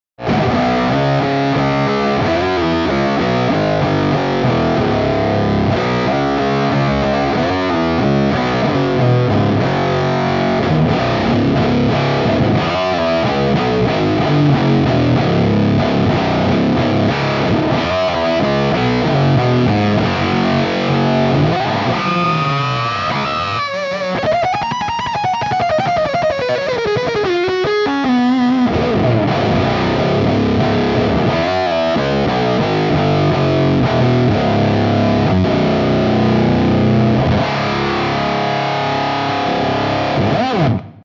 Cinnamon Toast (fuzz then octave)
Cinnamon-Toast.wav-fuzz-then-OCT.mp3